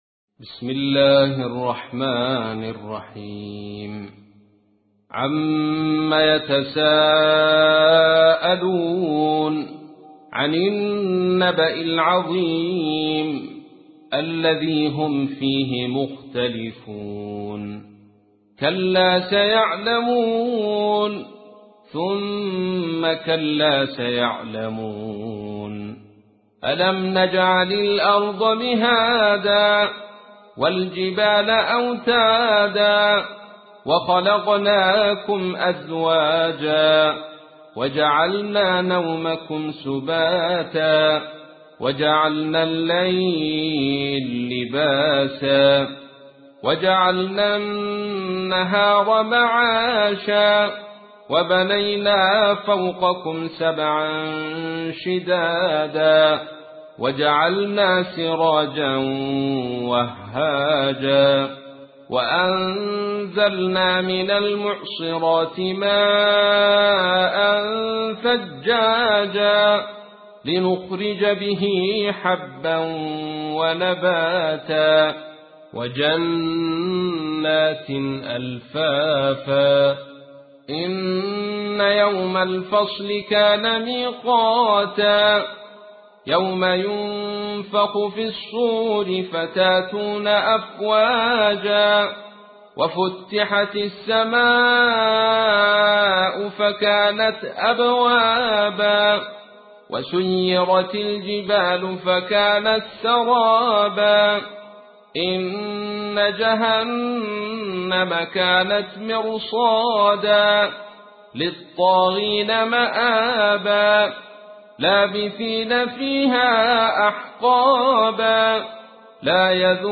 تحميل : 78. سورة النبأ / القارئ عبد الرشيد صوفي / القرآن الكريم / موقع يا حسين